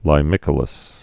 (lī-mĭkə-ləs)